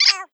whackaMole_hit.wav